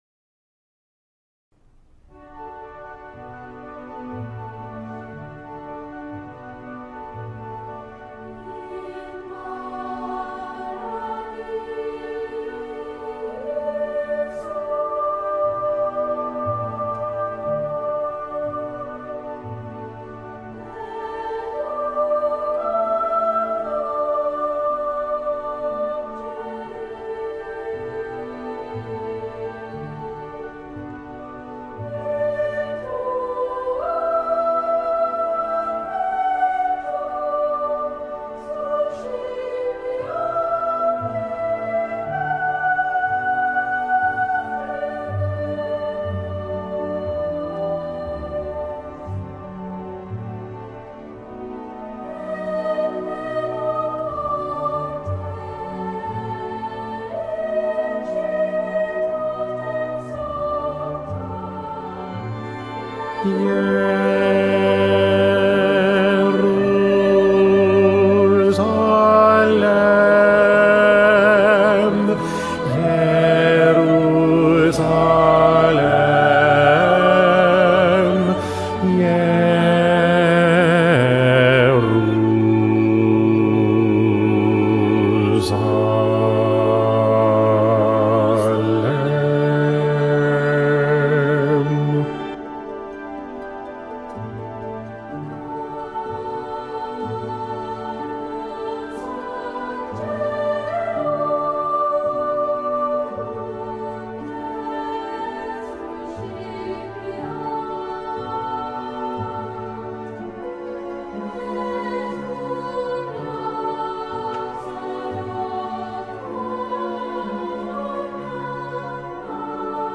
-Material didáctico-
Bajo I